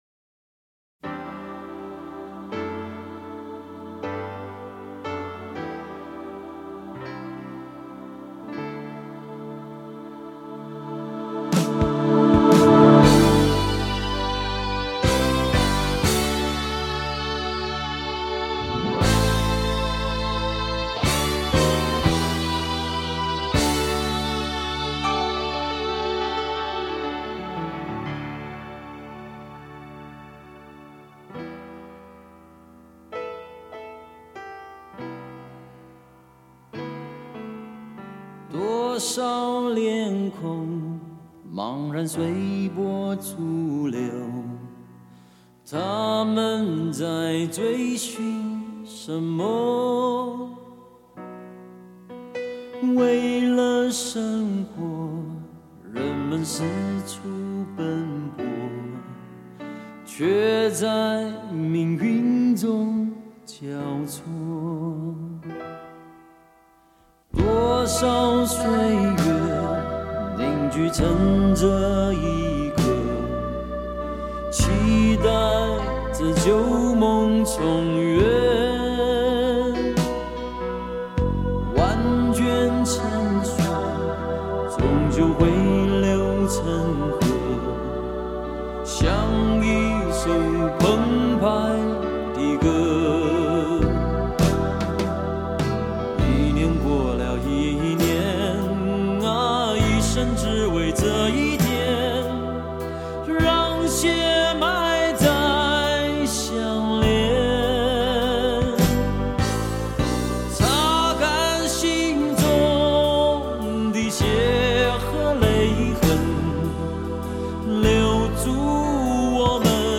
**本碟歌曲之原录音带采用24bit数码系统重新编制
**令音场透明度及层次感大大增加
**频应明显扩张，动态更具震撼